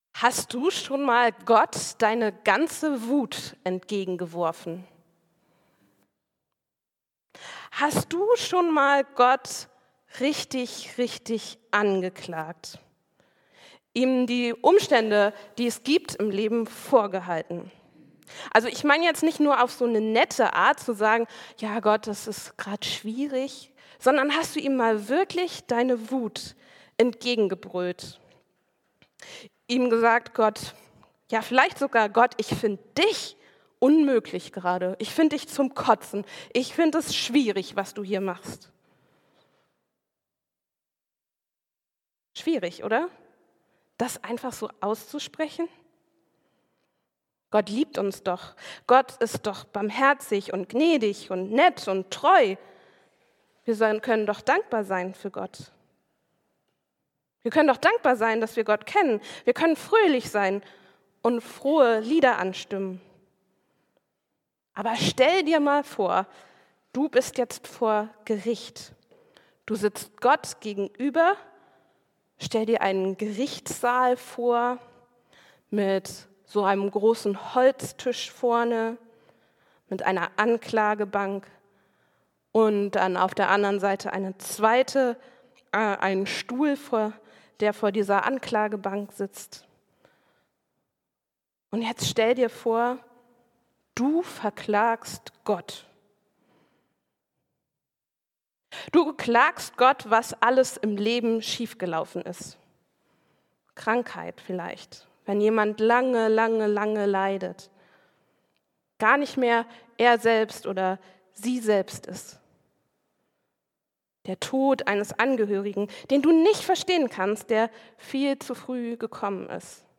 Wenn Gott verborgen bleibt: Hiobs Schrei ins Leere ~ Christuskirche Uetersen Predigt-Podcast Podcast